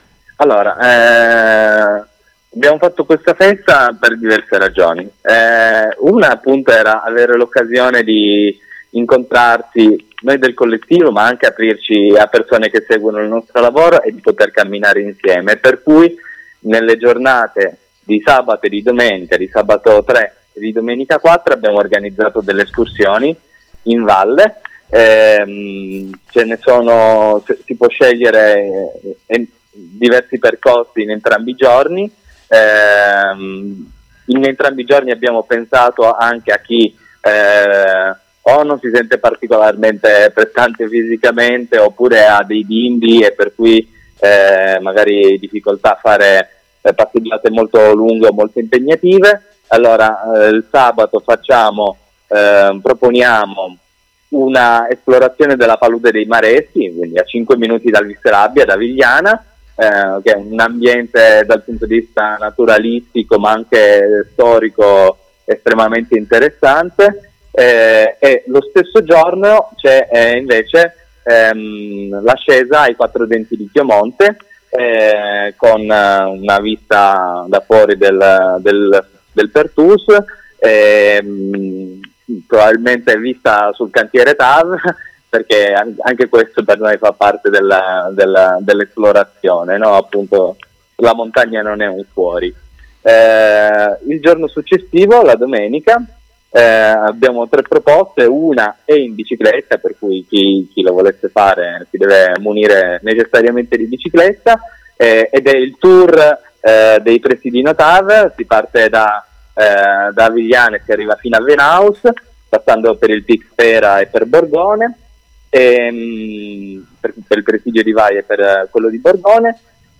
Abbiamo sentito Alpinismo Molotov, organizzatori della festa intitolata “Diverso il suo rilievo” presso lo spazio sociale VisRabbia di Avigliana. Abbiamo chiesto loro quali principi li ispirano e cosa hanno preparato per noi.